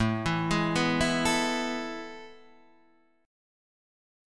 A Major 7th Suspended 2nd